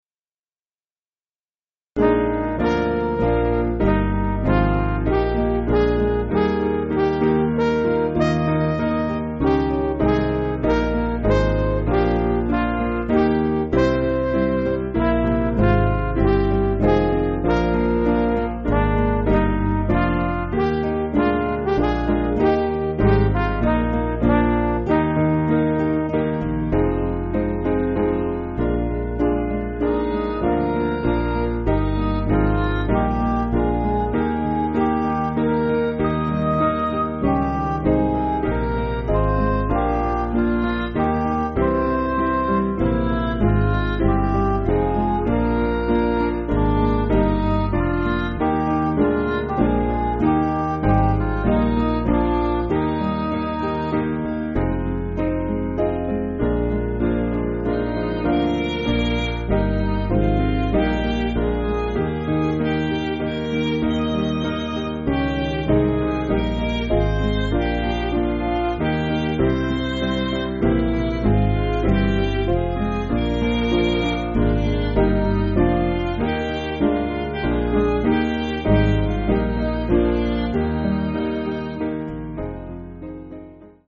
Piano & Instrumental
(CM)   5/Eb